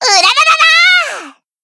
BA_V_Koyuki_Battle_Shout_2.ogg